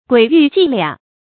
鬼蜮技倆 注音： ㄍㄨㄟˇ ㄧㄩˋ ㄐㄧˋ ㄌㄧㄚˇ 讀音讀法： 意思解釋： 見「鬼蜮伎倆」。